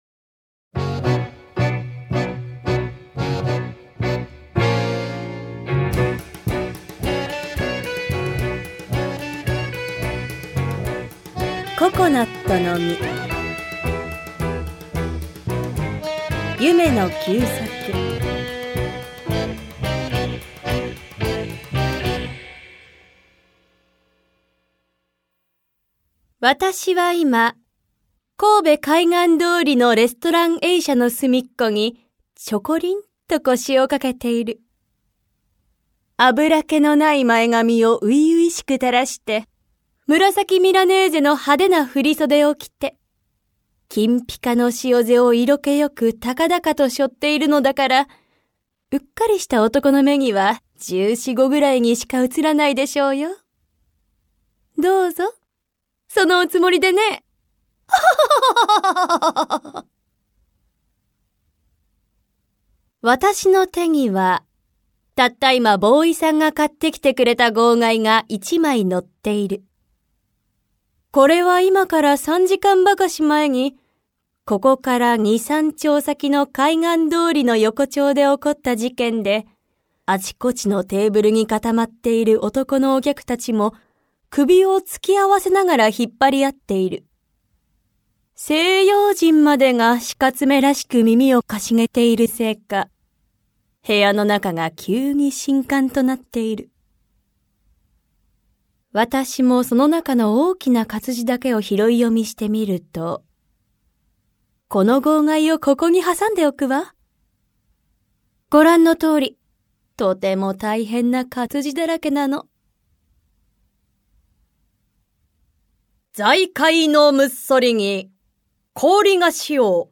[オーディオブック] 夢野久作「ココナットの実」